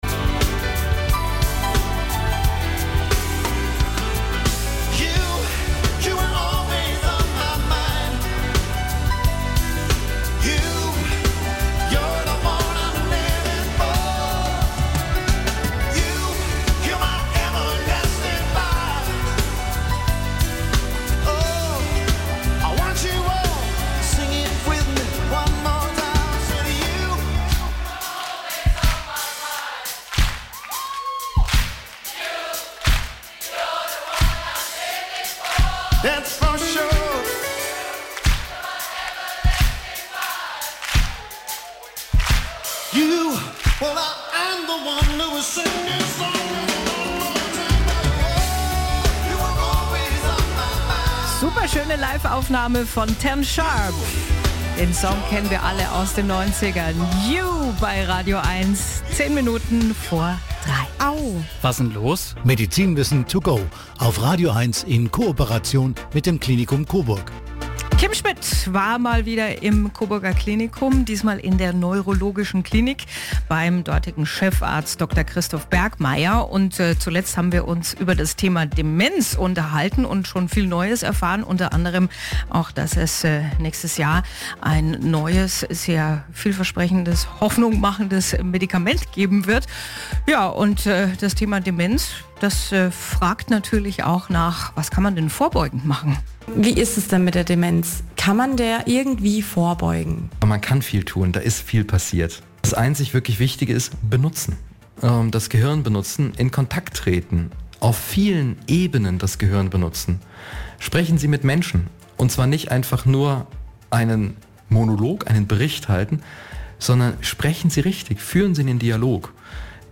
Unser Lokalsender Radio Eins im Experteninterview: